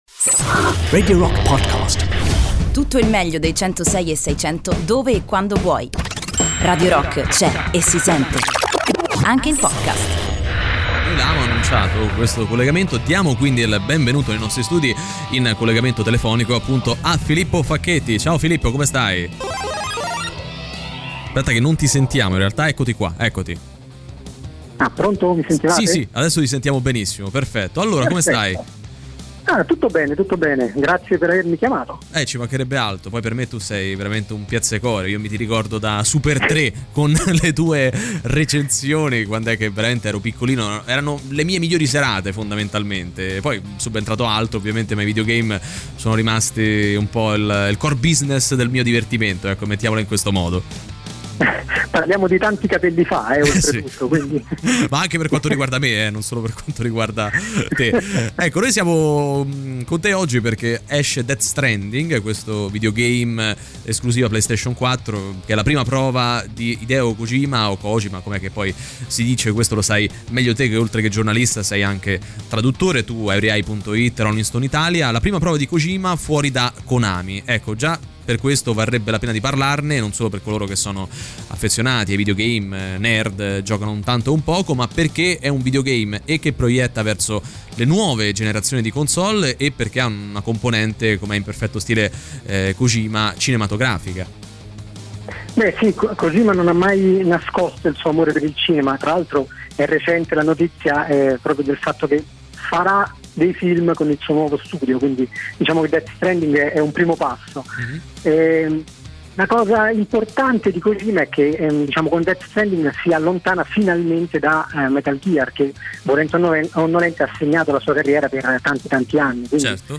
Il collegamento telefonico